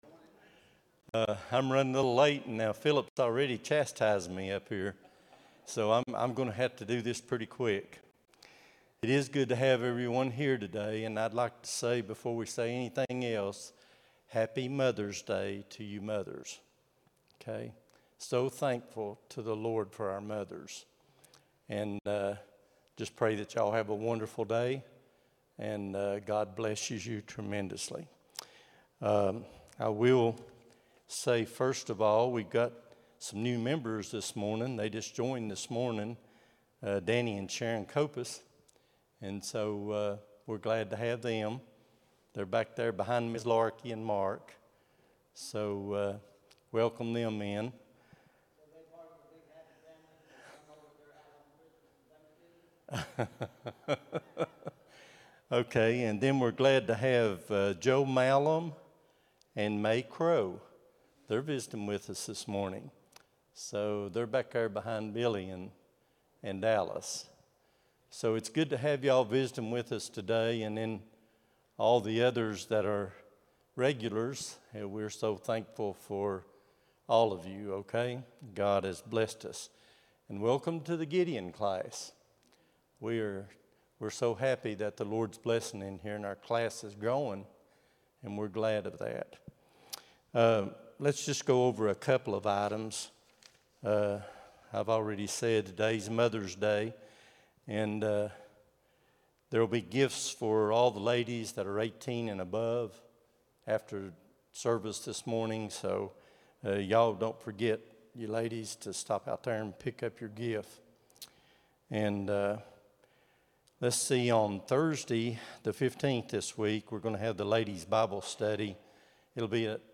05-11-25 Sunday School | Buffalo Ridge Baptist Church